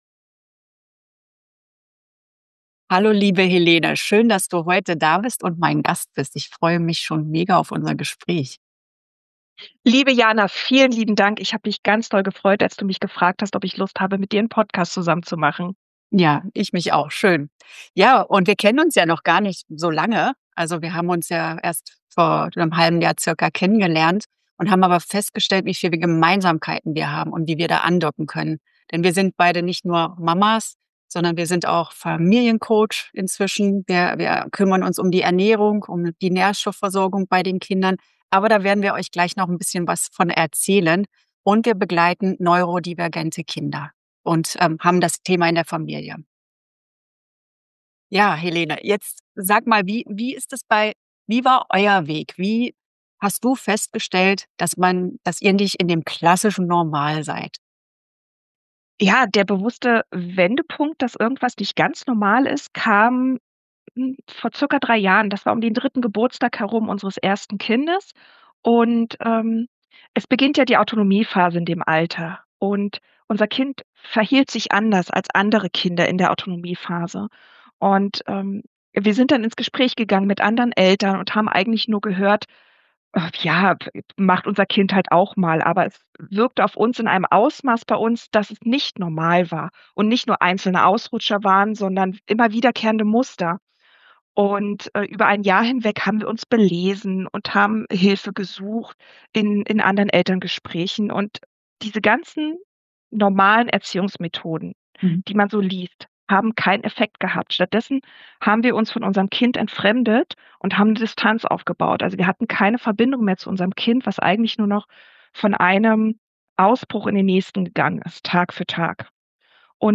Wir sprechen über die täglichen Herausforderungen, warum normale Erziehungsmethoden nicht funktionieren, wie wichtig es ist, erst bei uns selbst aufzuräumen, und welche erstaunliche Rolle Omega-3 und Nährstoffe spielen können. Ein Gespräch voller Hoffnung für alle Familien, die sich gerade fragen: "Sind wir allein?"